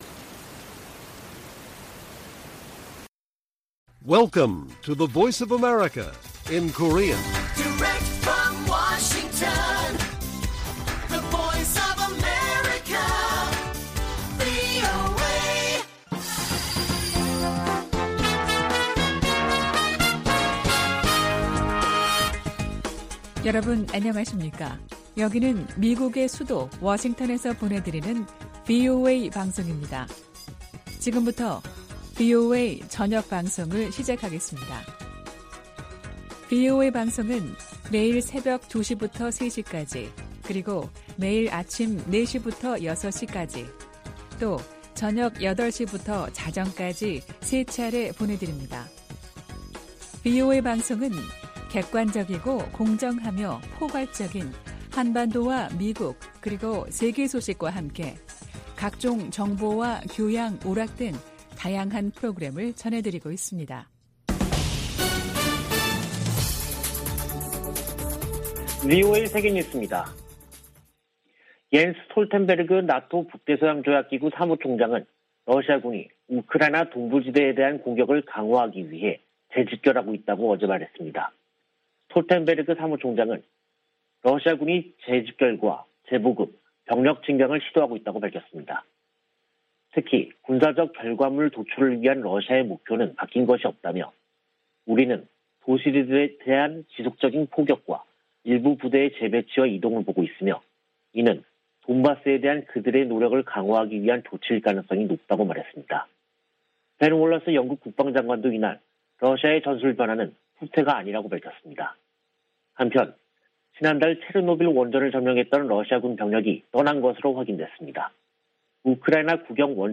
VOA 한국어 간판 뉴스 프로그램 '뉴스 투데이', 2022년 4월 1일 1부 방송입니다. 북한이 ICBM 발사에 이어 조기에 핵실험 도발에 나설 것이라는 전망이 나오고 있습니다. 미 국무부는 북한의 추가 도발 가능성을 주시하고 있다면서 추가 압박을 가하는 등 모든 일을 하고 있다고 강조했습니다.